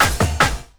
50LOOP02SD-L.wav